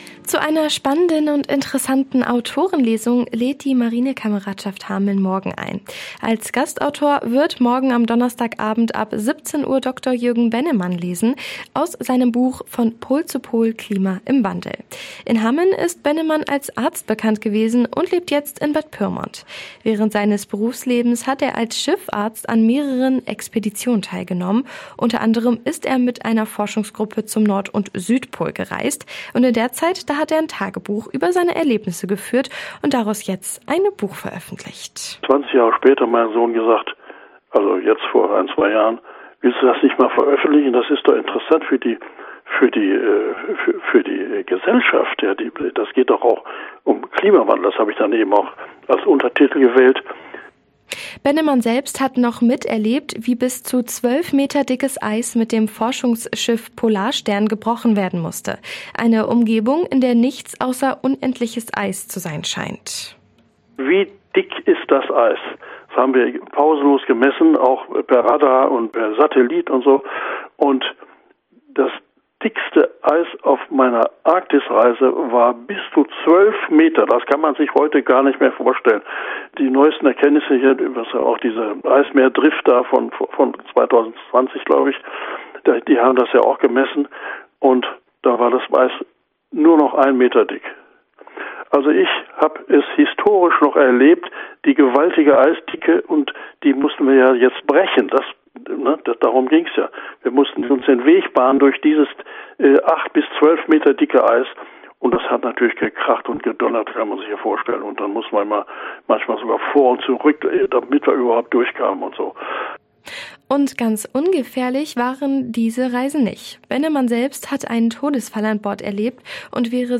Autorenlesung der Marinekameradschaft „Von Pol zu Pol“
Aktuelle Lokalbeiträge